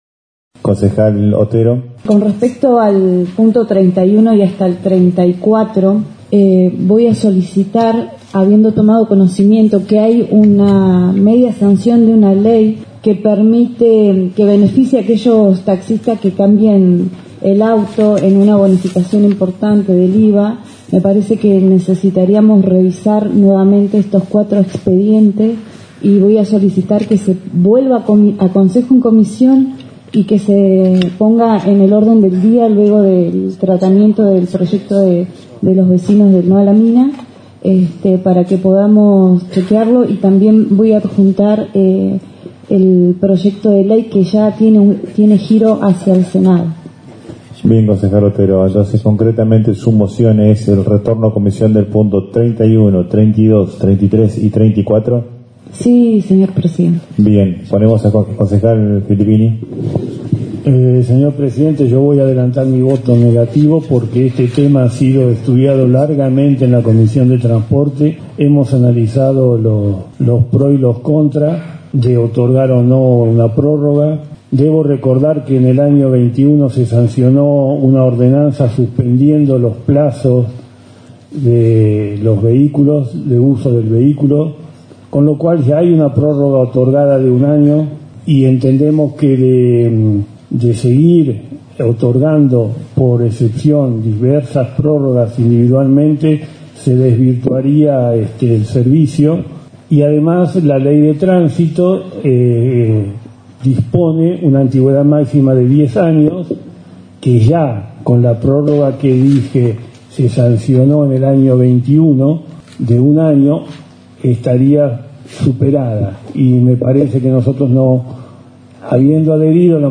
Al momento de tratar la situación de algunos taxistas, cuyos vehículos están en el límite del plazo de antigüedad, los concejales tuvieron el siguiente debate.
18°-sesion-hcd-presupuesto-5-plazo-renovacion-autos.mp3